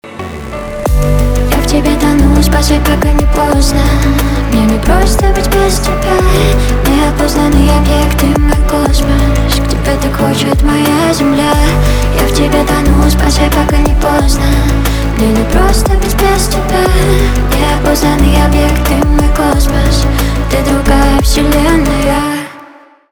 поп
романтические , чувственные , битовые , басы